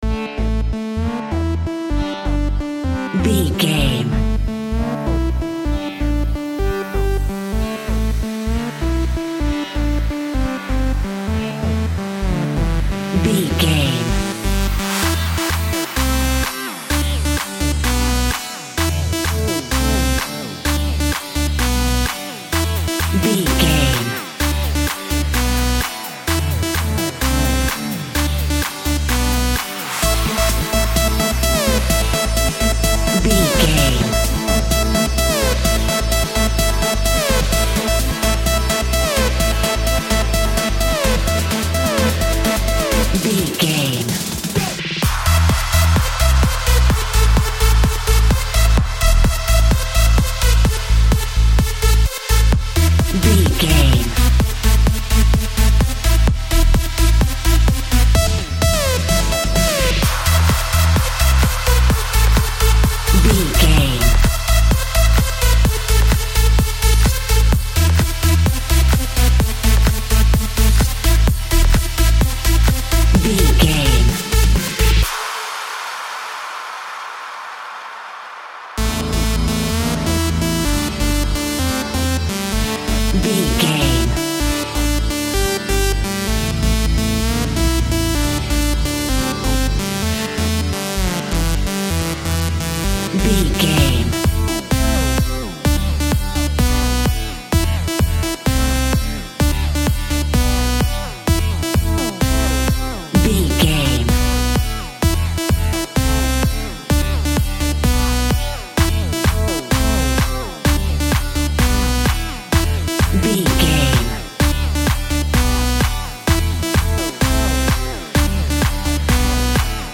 Trance Clubbing.
Aeolian/Minor
driving
energetic
uplifting
hypnotic
drum machine
synthesiser
acid house
electronic
uptempo
synth drums
synth leads
synth bass